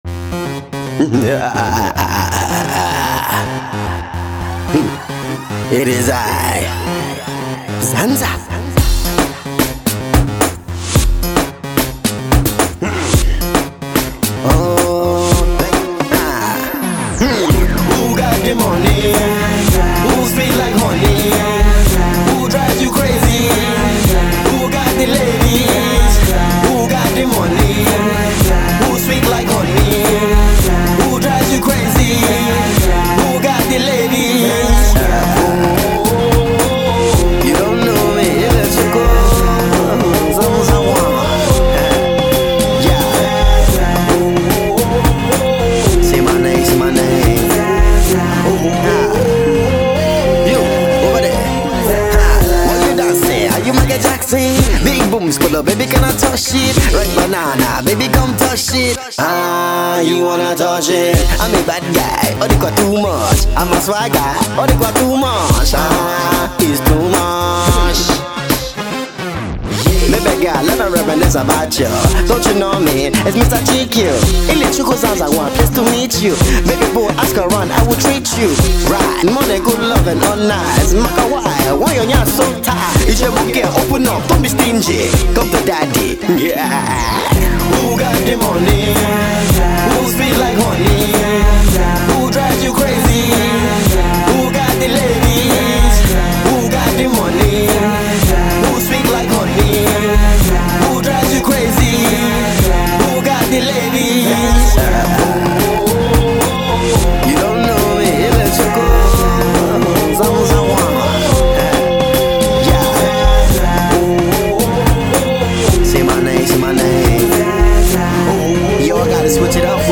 fun Afro Pop single